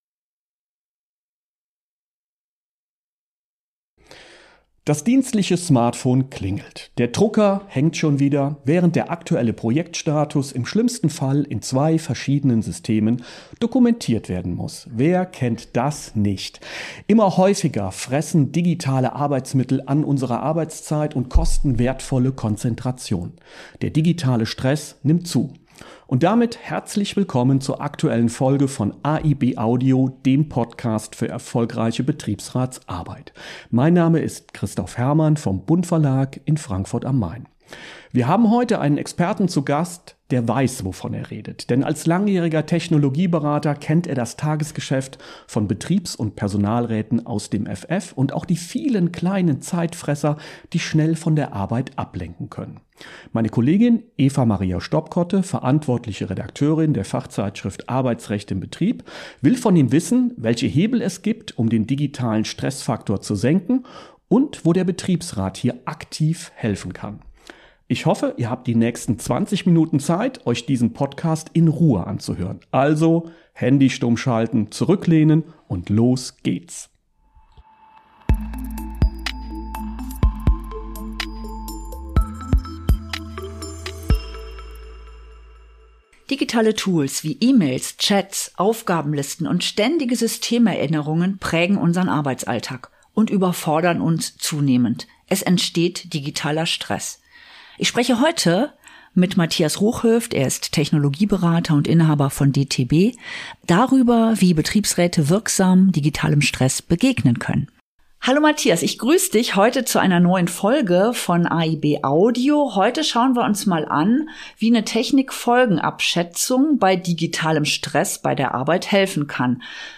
In AiB Audio zeigen Betriebsräte, wie sie sich erfolgreich für ihre Beschäftigten eingesetzt haben und geben anschaulich Tipps zur Umsetzung auch in anderen Betrieben. Namhafte Experten beleuchten aktuelle Trends in Rechtsprechung, Politik und betrieblichen Praxis.
… continue reading 53 حلقات # Bildung # Bund-Verlag # Betriebsrat # Arbeitsrecht # Interviews # Recht # Für Betriebsräte